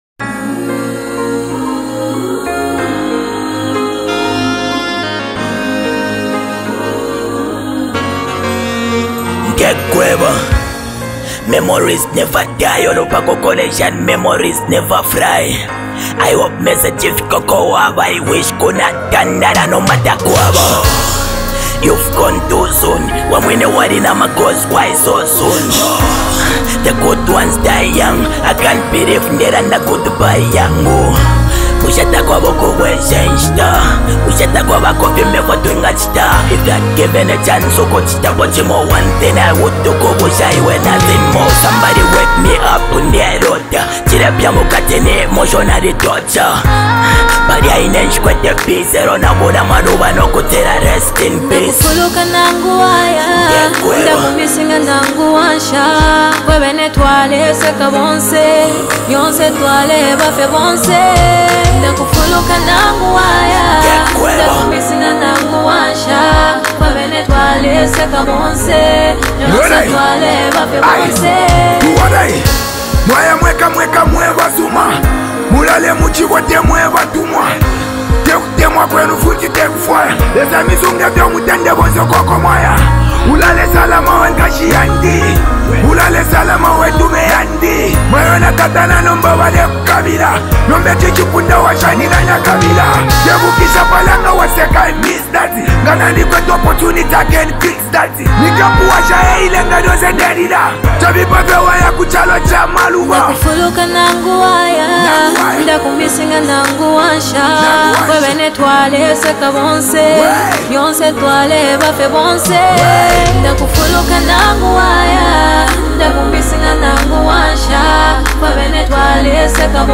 deeply emotional and melodically rich track